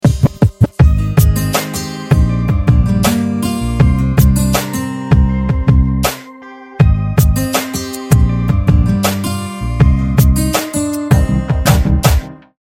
رینگتون نرم و باکلام